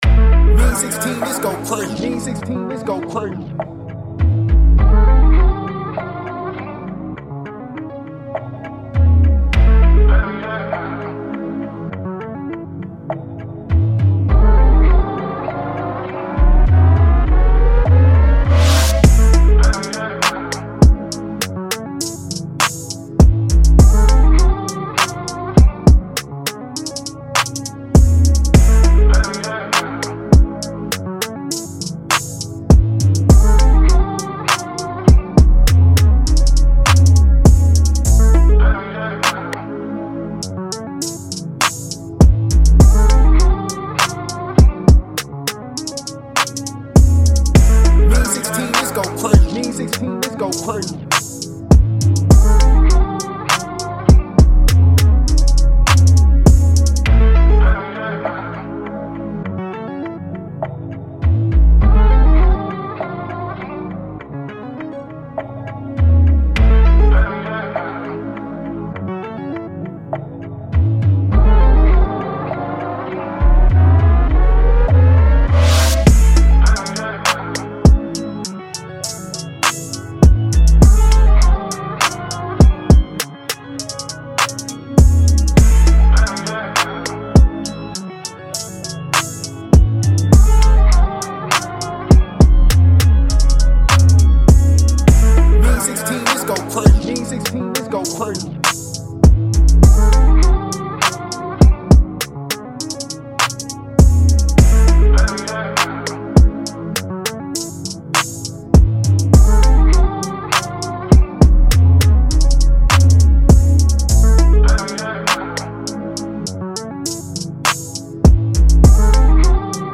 A-Min 101-BPM